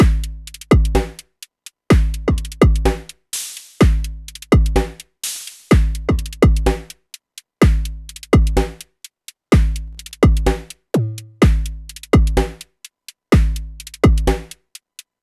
• drum sequence analog 3 broken beat - Am - 126.wav
Recorded internal, using a Volca Drum, modulated and a Komplete Audio 6 interface.
drum_sequence_analog_3_broken_beat_-_Am_-_126_LXC.wav